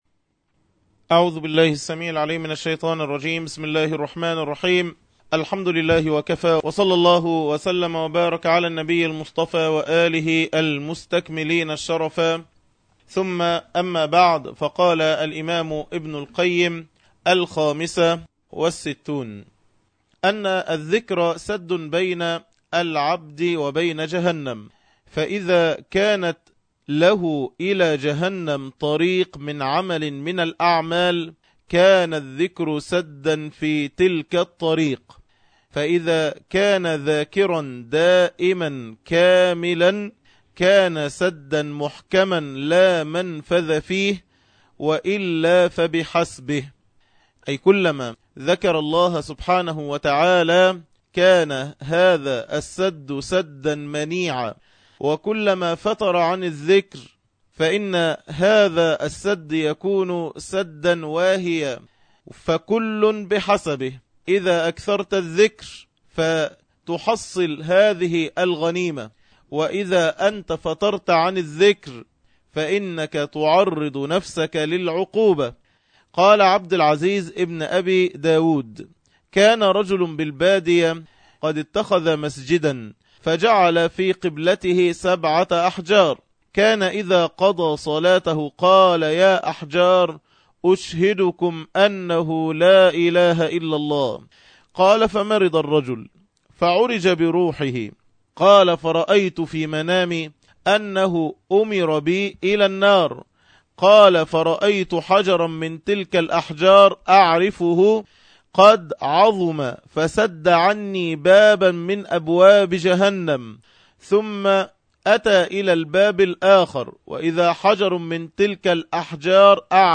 عنوان المادة الدرس الثامن عشر(الوابل الصيب) تاريخ التحميل السبت 30 يونيو 2012 مـ حجم المادة 18.21 ميجا بايت عدد الزيارات 1,110 زيارة عدد مرات الحفظ 423 مرة إستماع المادة حفظ المادة اضف تعليقك أرسل لصديق